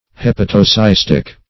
Search Result for " hepatocystic" : The Collaborative International Dictionary of English v.0.48: Hepatocystic \Hep`a*to*cys"tic\, a. [Hepatic + cystic.] (Anat.) Of or pertaining to the liver and gall bladder; as, the hepatocystic ducts.